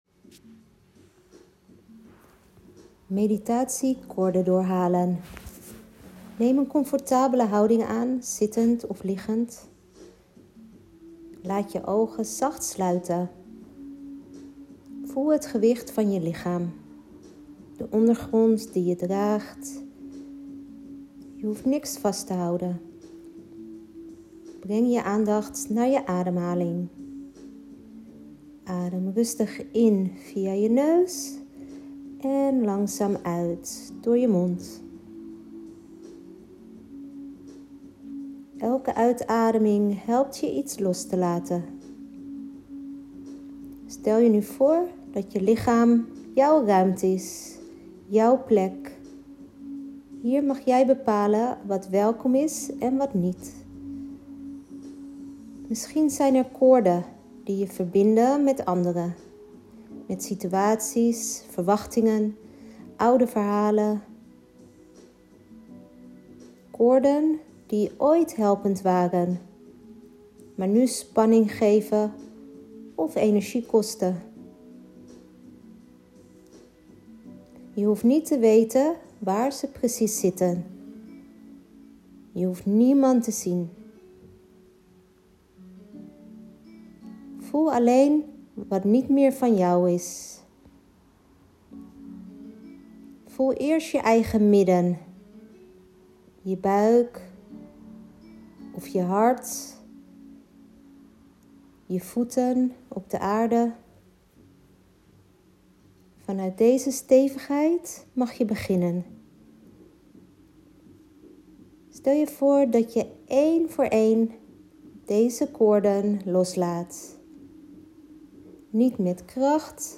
Het enige wat je hoeft te doen, is mijn stem volgen en je overgeven aan de meditatie of ademhalingsoefening.